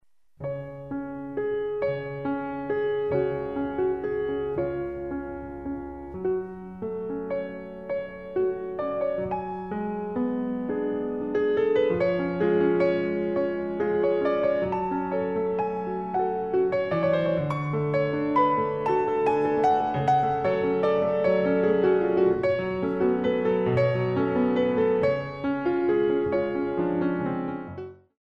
Compositions for Ballet Class
Some very Classical plus some jazz - ragtime rhythms
The CD is beautifully recorded on a Steinway piano.
Ronds de jambe a terre